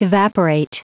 Prononciation du mot evaporate en anglais (fichier audio)
Prononciation du mot : evaporate